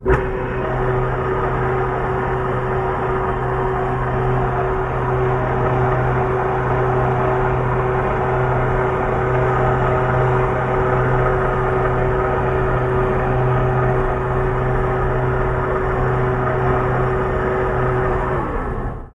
На этой странице собраны звуки торпед: от момента запуска до подводного движения и взрыва.